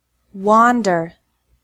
The WAN of wander is like the WAN of “want.”